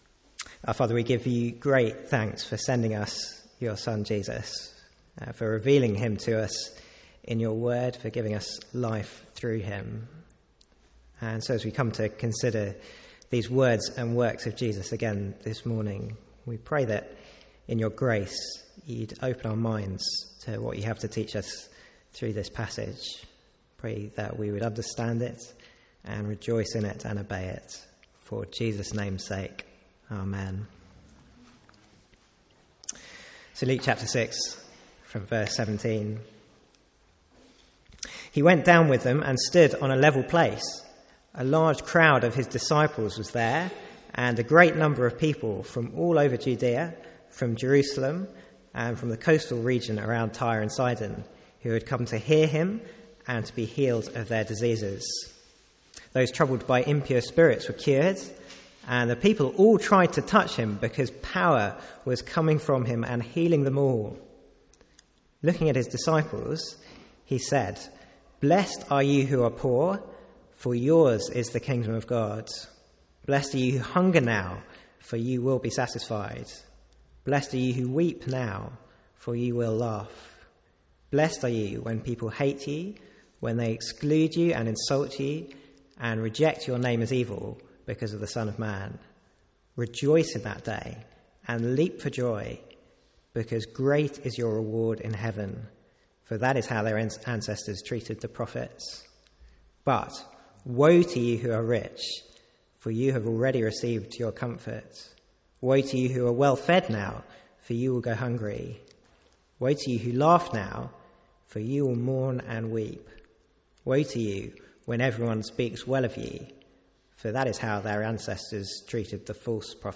Sermons | St Andrews Free Church
From the Sunday morning series in Luke's gospel.